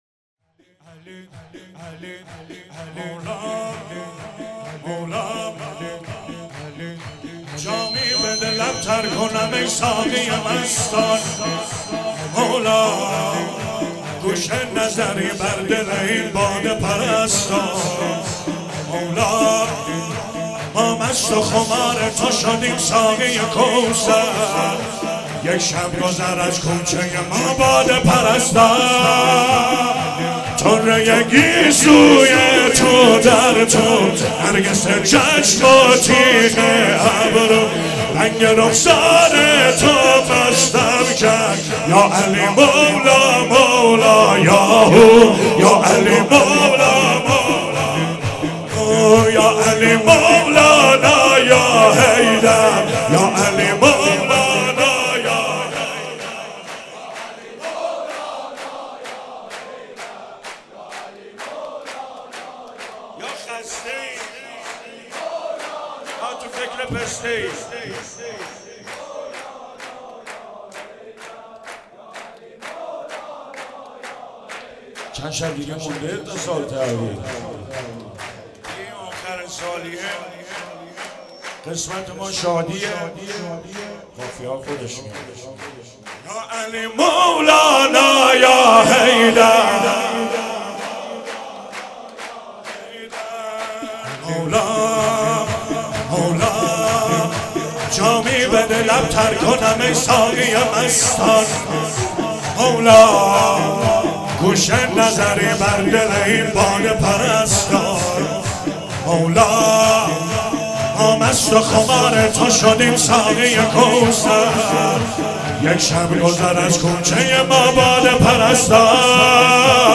مراسم هفتگی 26 اسفند 95
چهاراه شهید شیرودی حسینیه حضرت زینب (سلام الله علیها)
شور